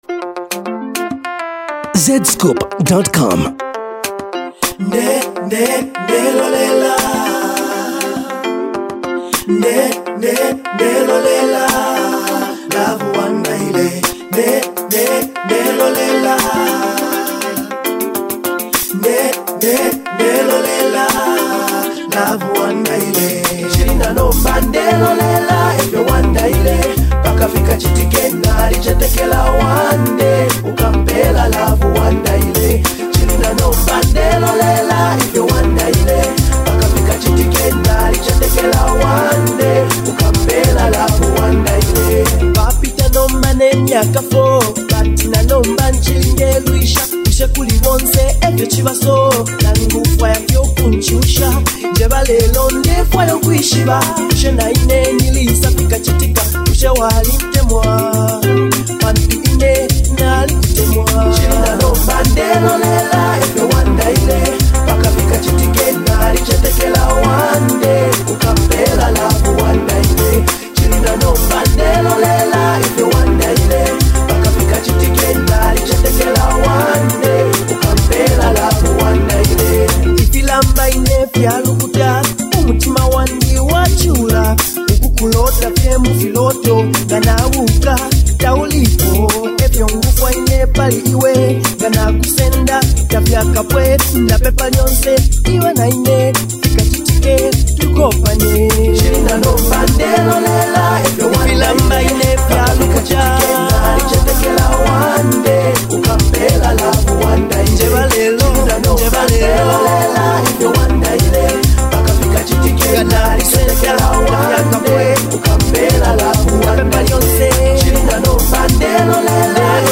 Old Zambian Music
Legendary Zambian male singer